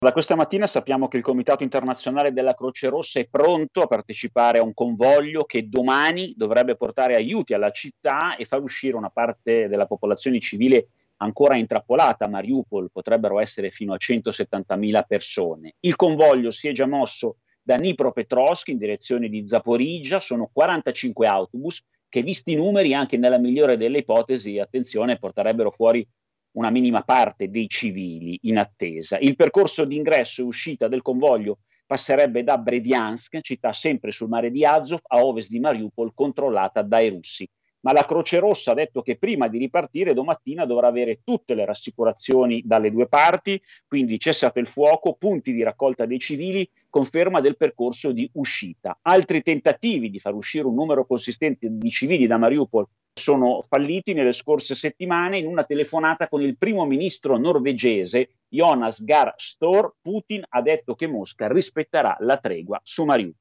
In Ucraina in queste ore si aspetta di capire se finalmente ci sarà un’importante evacuazione dalla città ucraina di Mariupol, assediata da settimane. Il servizio